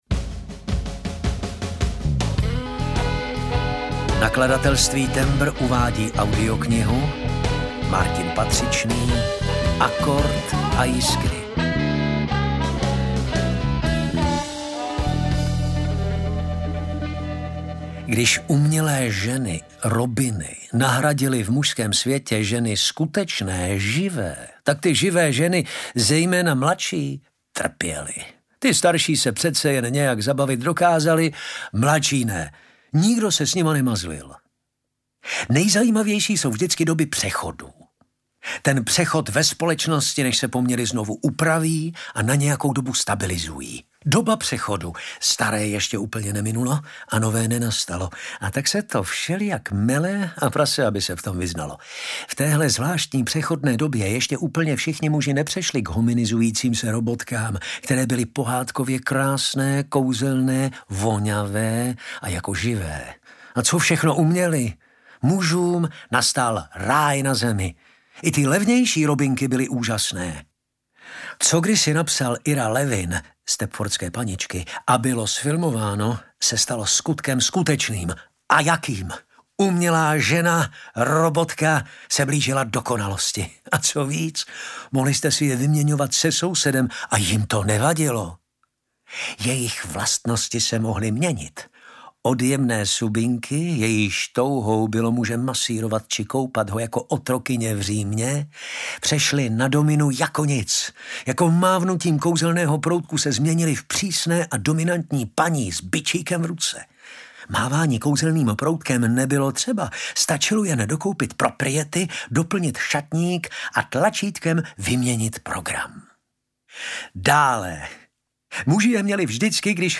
Akord a Jiskry audiokniha
Ukázka z knihy